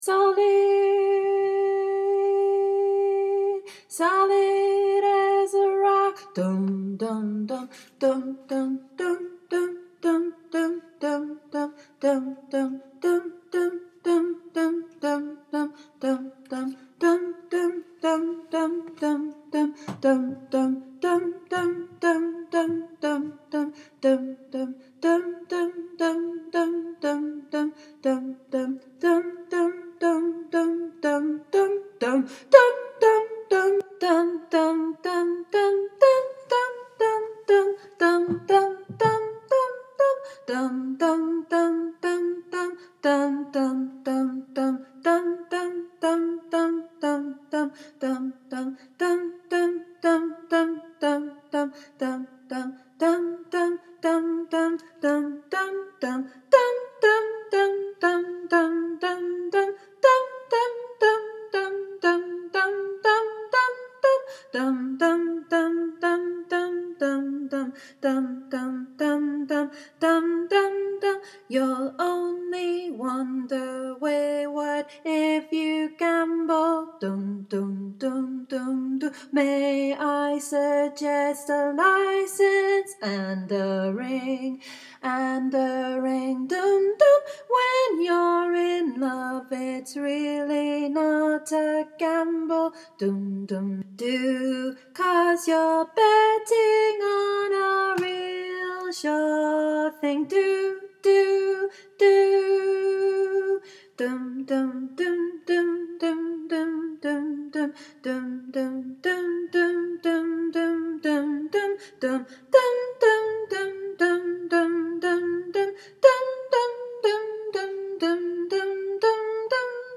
solid-as-a-rock-bass.mp3